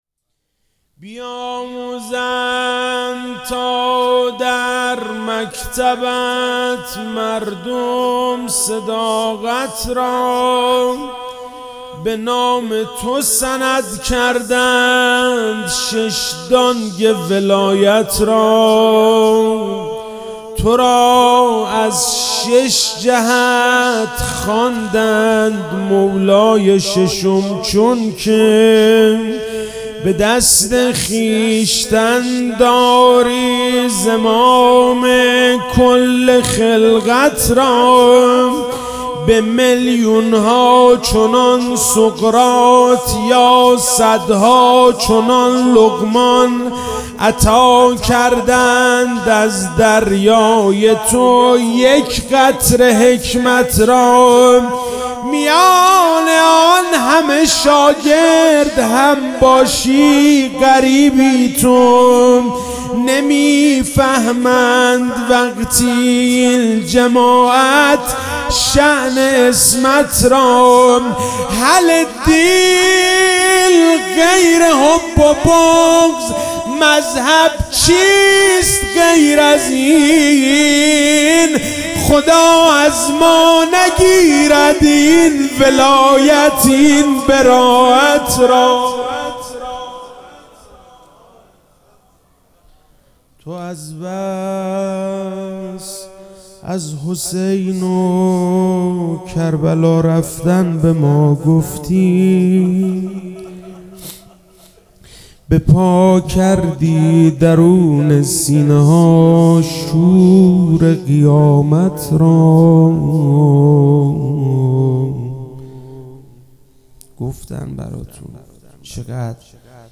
شهادت امام جعفرصادق(علیه السلام)-روضه-بیاموزند صداقت را